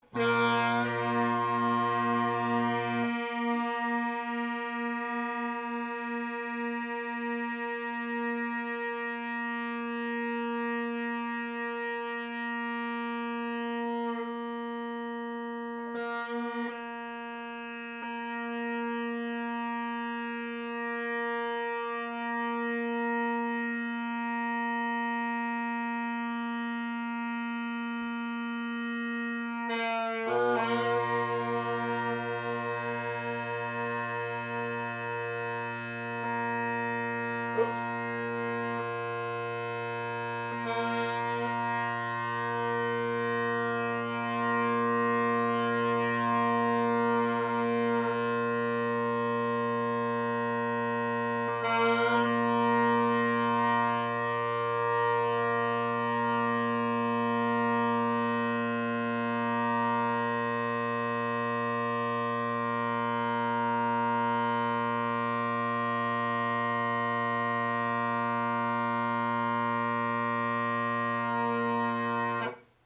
The glass Rockets below are definitely bolder than the carbon Rockets above (same recording conditions, sorry there’s no chanter).